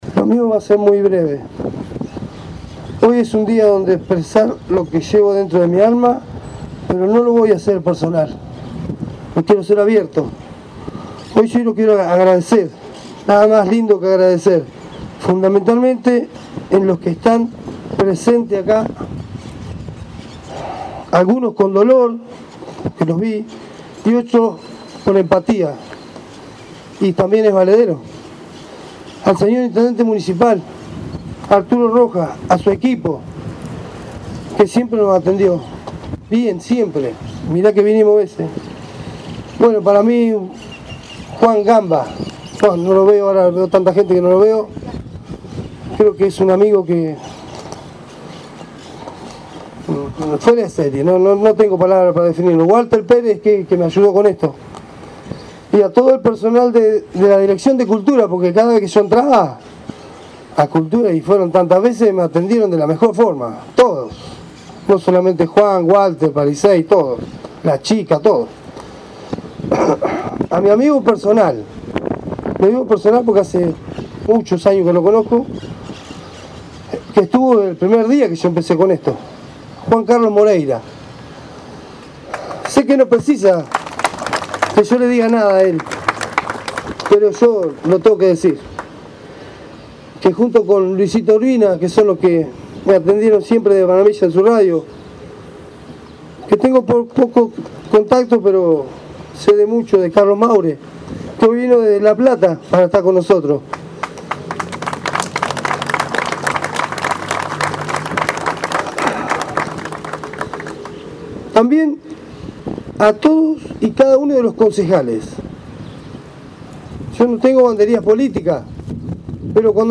En un sentido acto en la Plaza, se descubrió el Monumento a las Víctimas del Covid 19